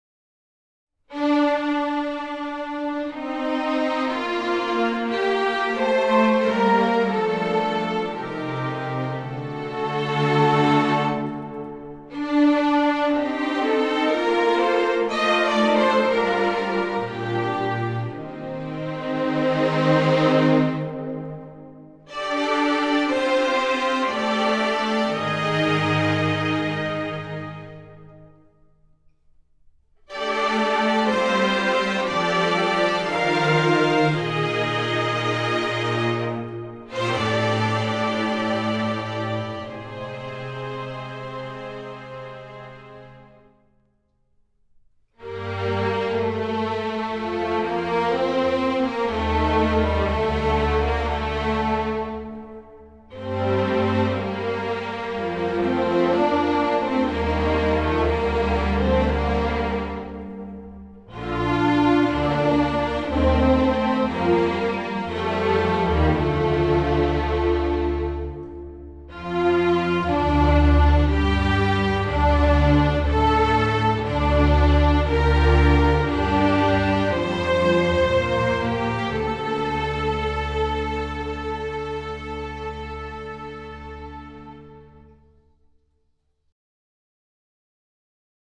电视原声音乐
以弦乐群组为主线条，并以攀爬缓升的多声部乐声营造出昂扬的气势